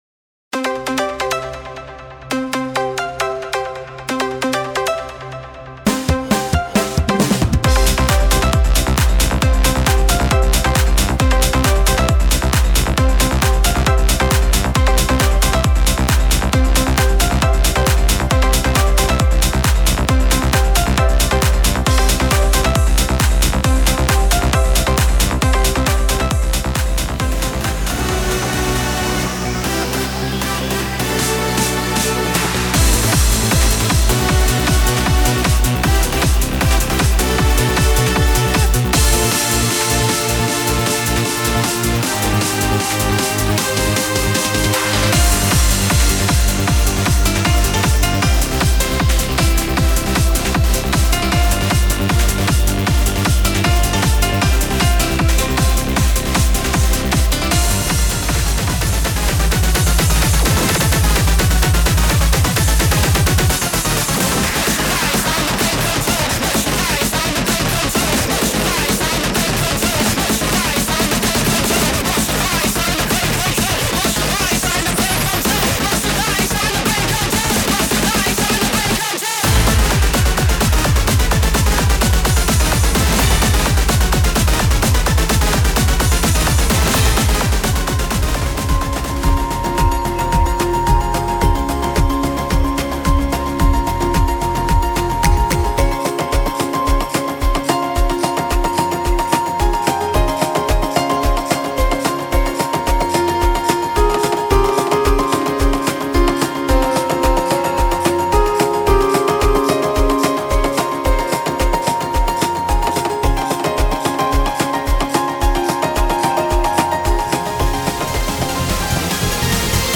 試聴（クロスフェード）
amapiano
SpeedTechno
HardBass